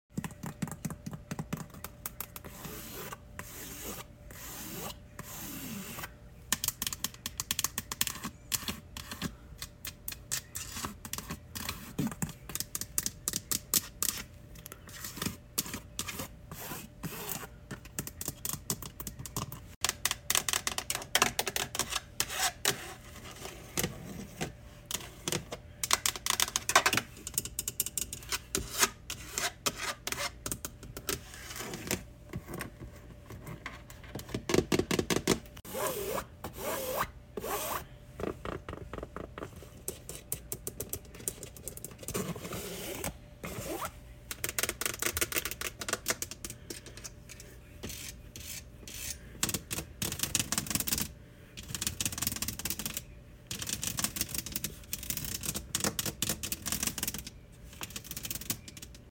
Asmr On Some Random Suitcases Sound Effects Free Download